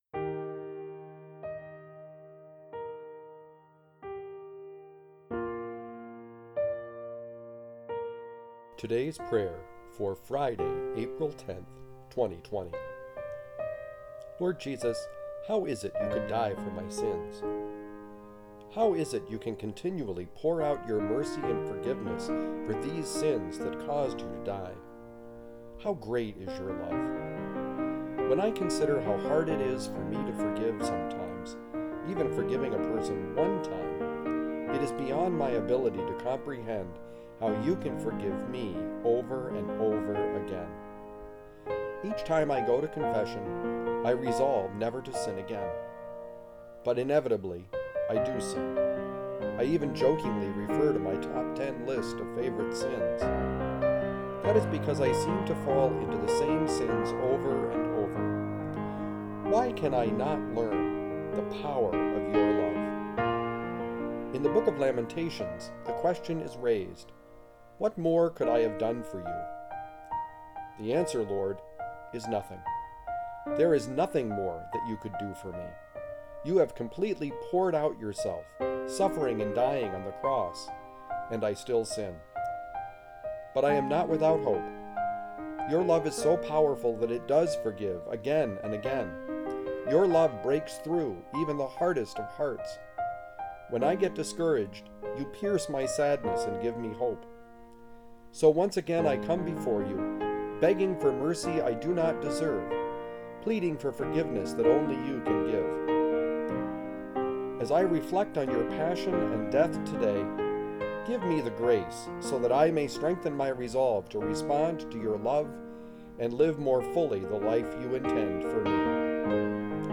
Music: Cheezy Piano Medley by Alexander Nakarada